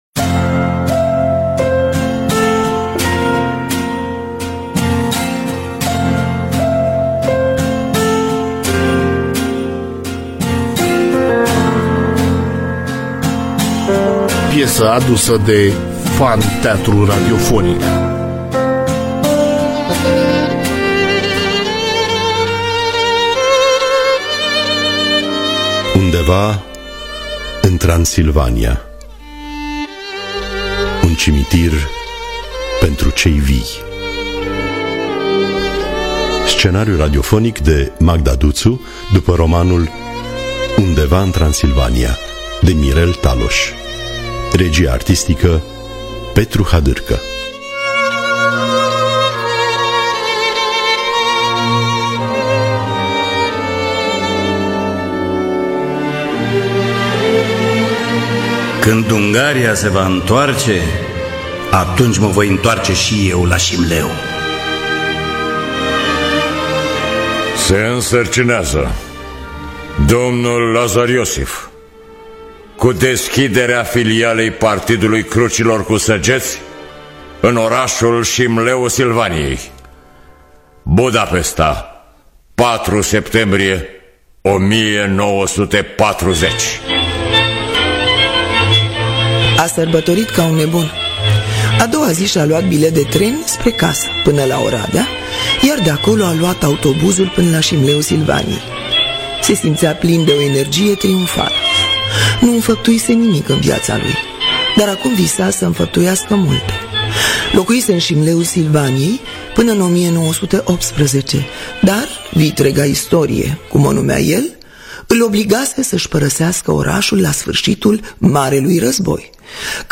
Un Cimitir Pentru Cei Vii (2020) – Teatru Radiofonic Online